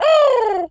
pigeon_call_angry_02.wav